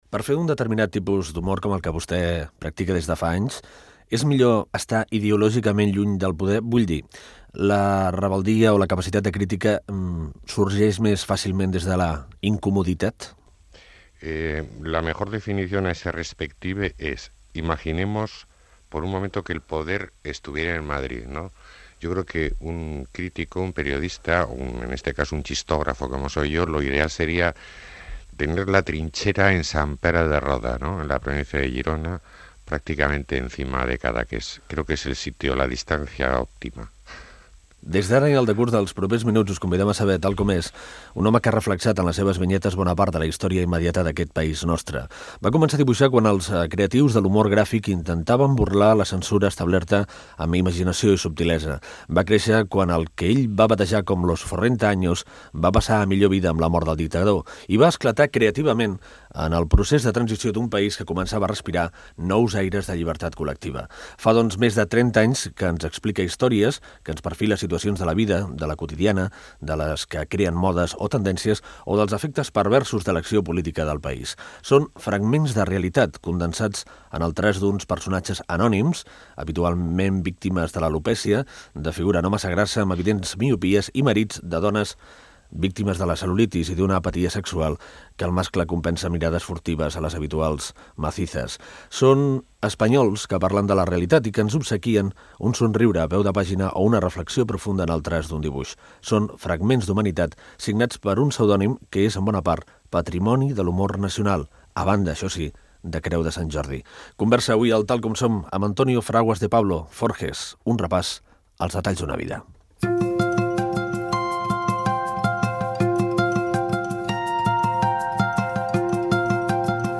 Fragment d'una entrevista a l'humorista Antonio Fraguas "Forges".